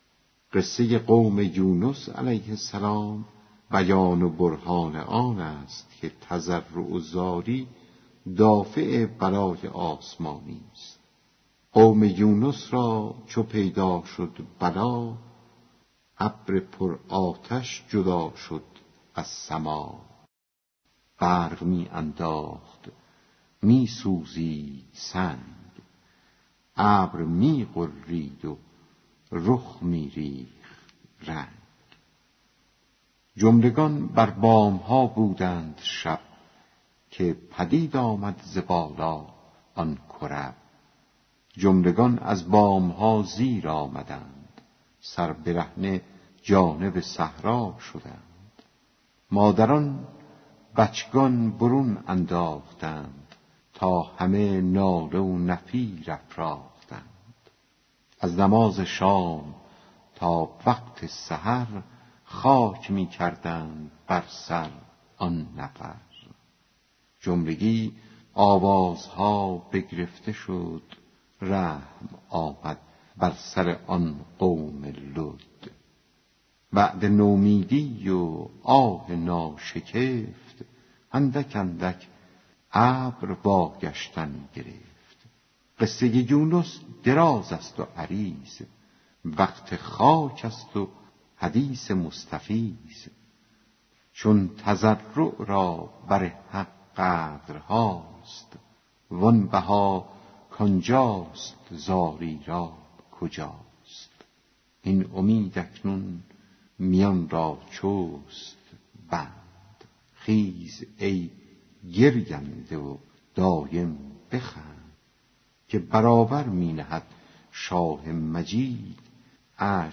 دکلمه قصه قوم یونس برهان آن است که زاری دافع بلاست